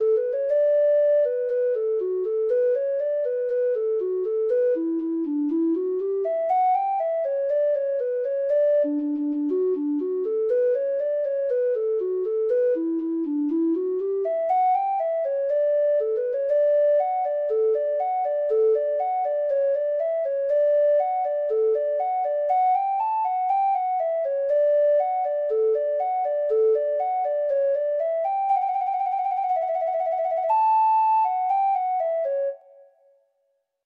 Treble Clef Instrument version
Reels